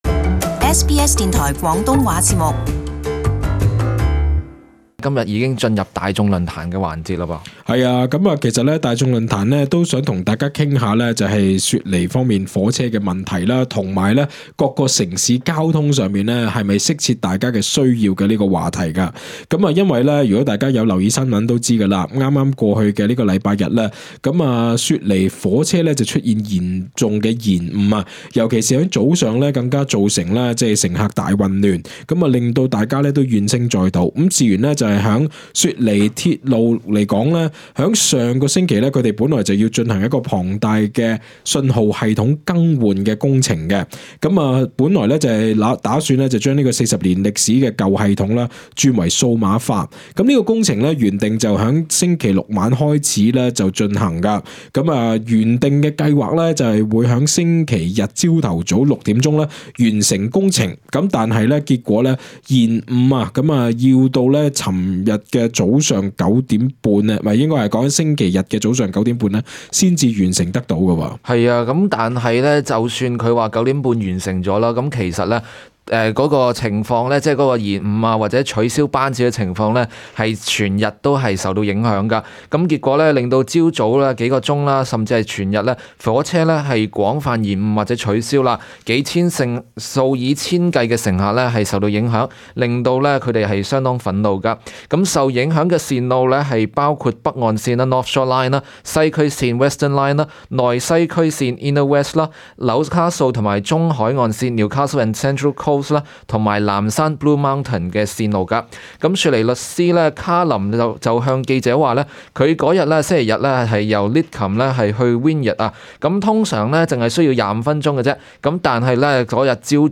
請聽眾朋友分享他們是否認為所居住的城市的交通系統能夠切合他們的需要呢？